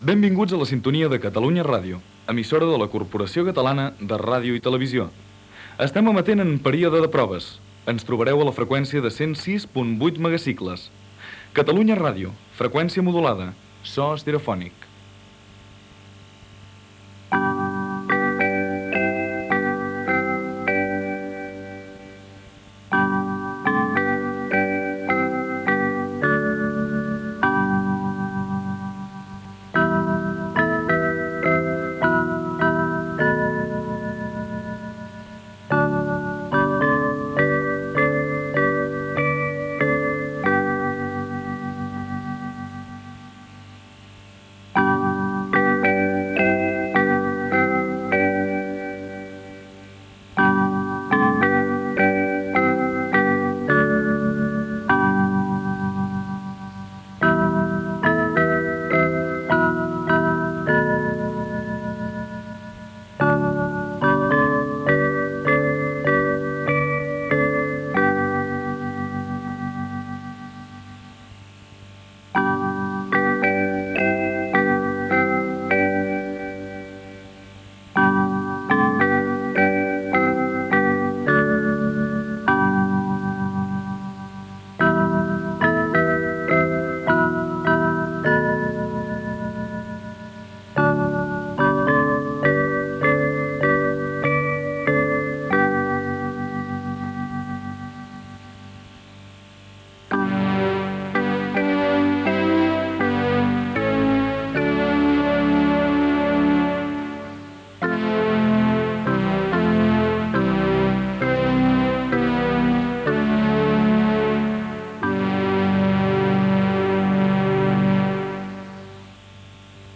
Primer dia d'emissió. Identificació i sintonia del període de proves.
FM
La sintonia del període de proves era una versió de la melodia de "Els Segadors" creada i interpretada per Lluís Llach i enregistrada a la seva casa de Parlavà.